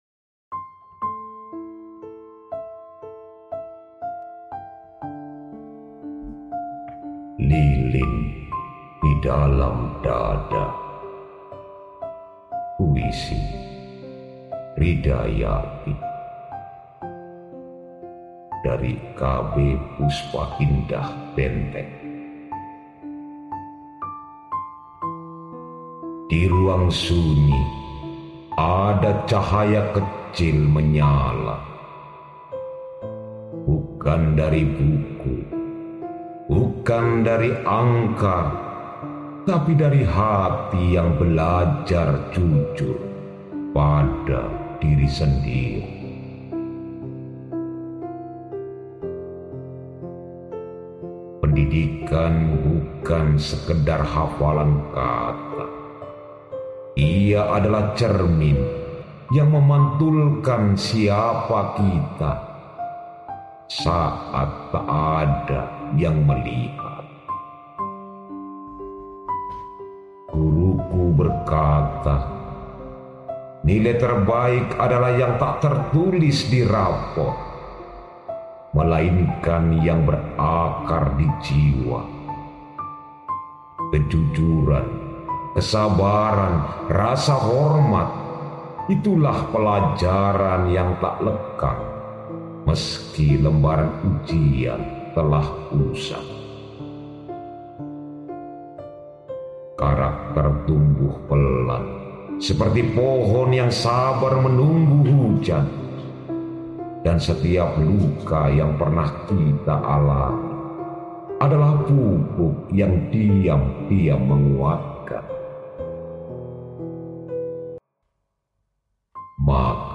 dibacakan oleh